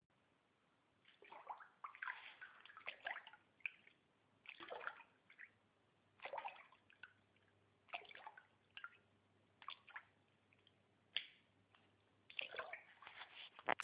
描述：西班牙，巴达霍斯。热水池中的水声。
Tag: 水疗